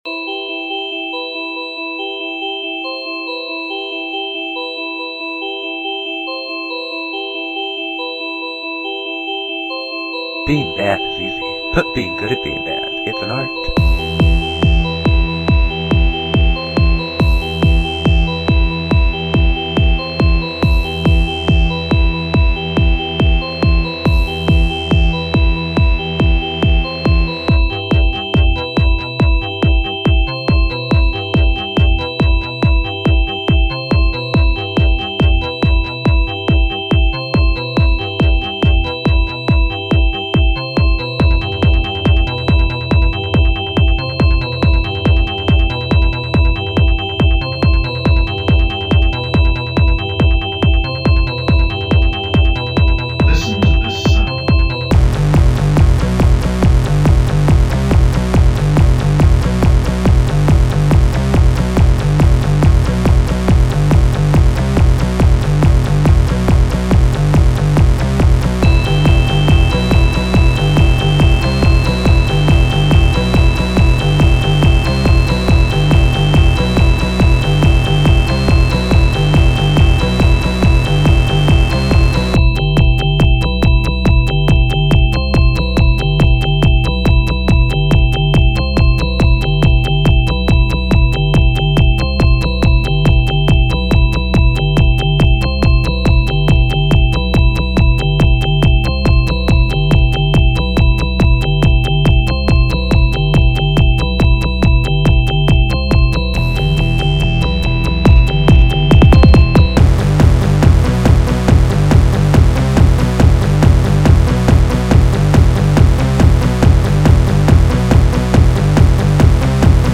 Genre: Break-Core
My sound is different.
Break-Core